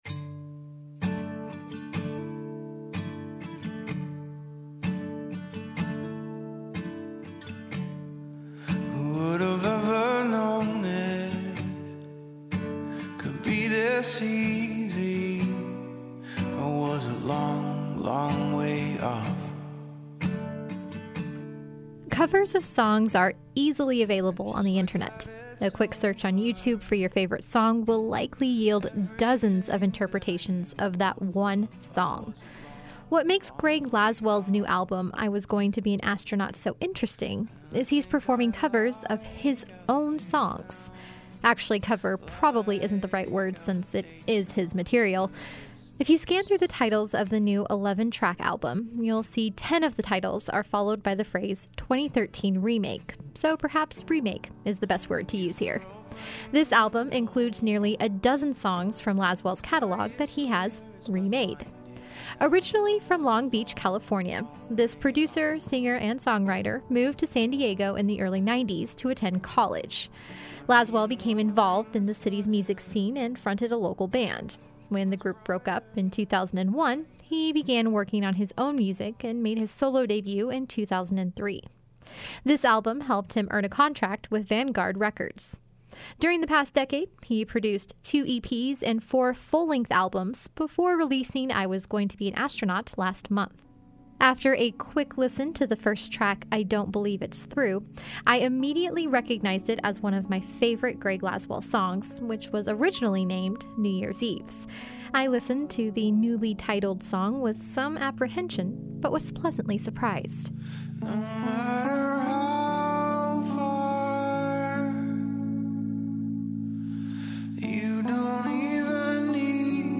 Music Review: Greg Laswell